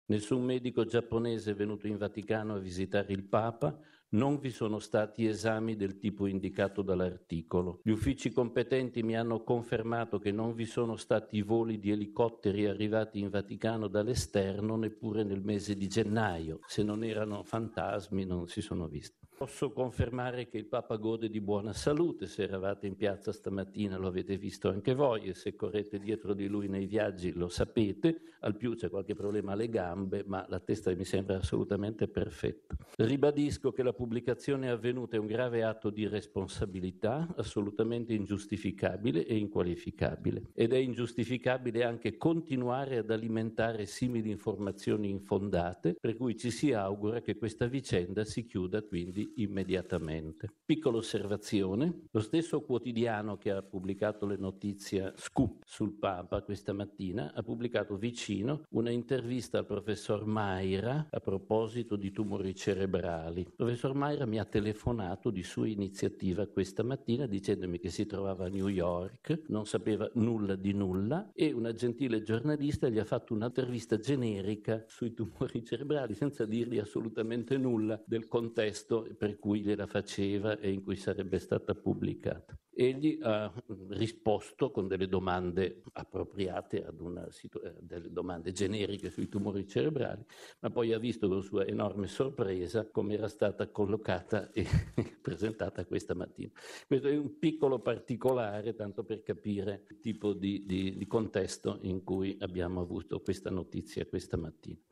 Padre Lombardi, all’inizio del briefing odierno sul Sinodo, ha ribadito con forza la sua smentita, già diffusa ieri sera, dopo le verifiche fatte con le fonti opportune, compreso il Santo Padre.